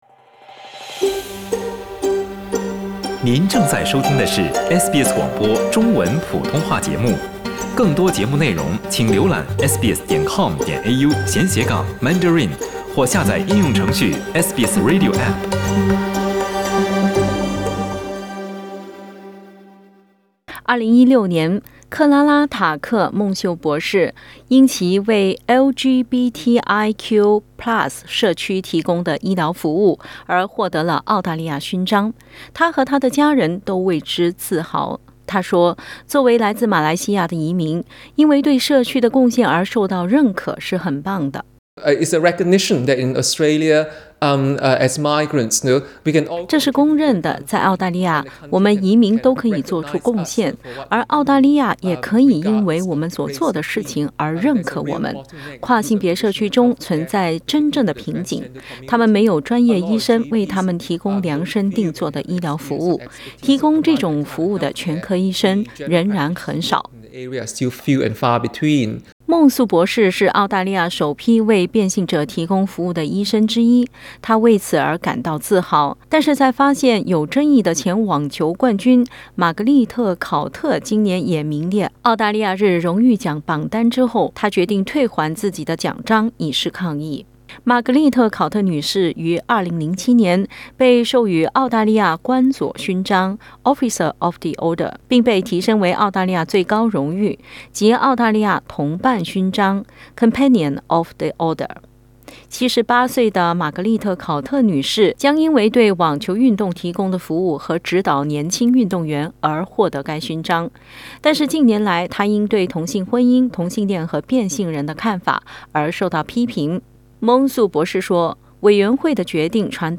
（请听报道） 澳大利亚人必须与他人保持至少 1.5 米的社交距离，请查看您所在州或领地的最新社交限制措施。